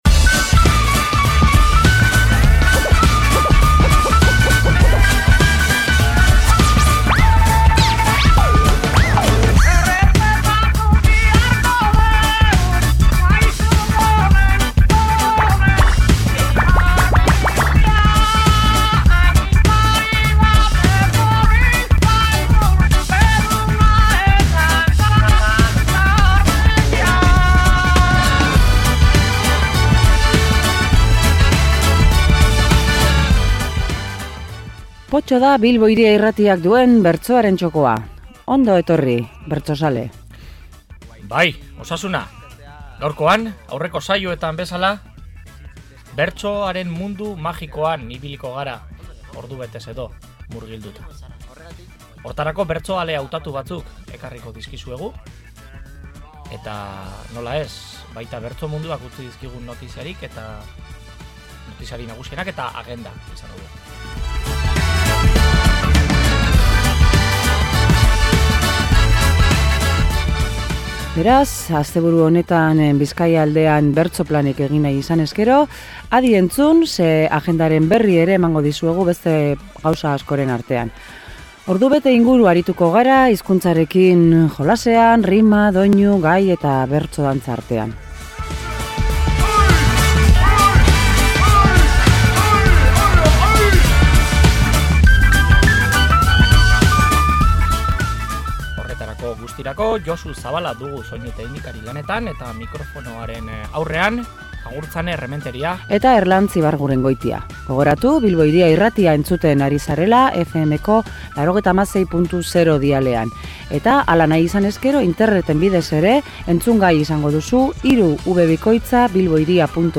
Gaurkoan Aramaion jokatutako Arabako txapelketaren bigarren finalaurrekoa aipatu dugu. Horrekin batera, Billabonan zein Mungian izandako bertso-saioak entzun (zati batzuk) eta komentatu egin ditugu.